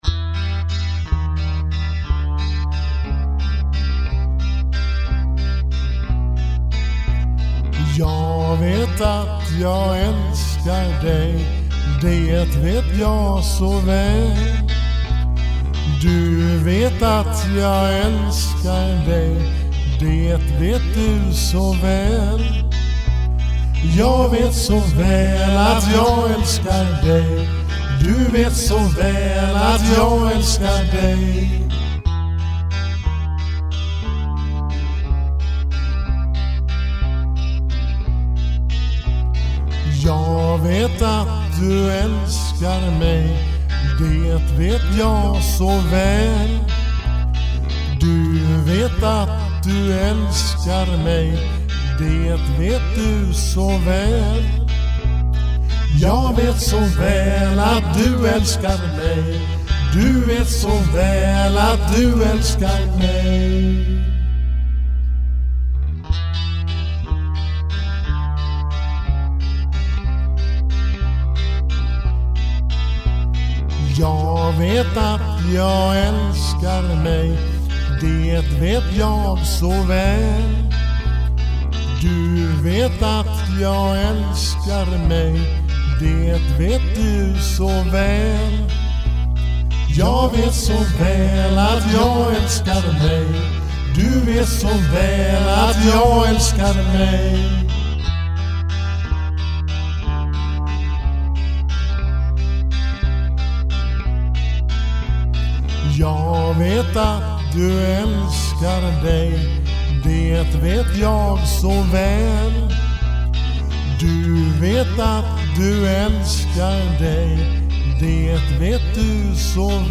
Valde ¾ takt.
En av mina få låtar utan trummor.